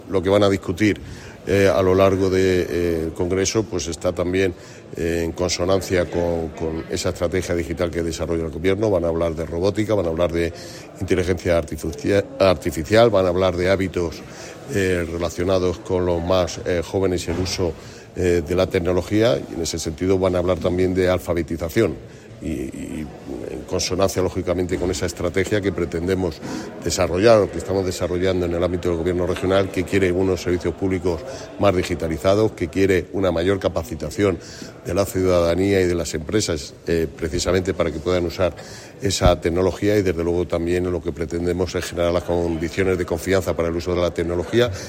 Consejería de Hacienda, Administraciones Públicas y Transformación Digital Sábado, 10 Mayo 2025 - 12:00pm Durante la apertura del tercer Congreso de Digitalización Educativa en Albacete, Ruiz Molina que la línea de este congreso está en consonancia con la estrategia digital del Gobierno de Castilla-La Mancha. Así, se van a abordar áreas como la robotización, la Inteligencia Artificial o hábitos relacionados con el uso de la tecnología entre los jóvenes o la alfabetización mediática.